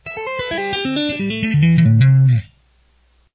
Tapping Examples
It involves all six strings.
tapping_3.mp3